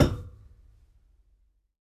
tbd-station-14/Resources/Audio/Effects/Footsteps/hull3.ogg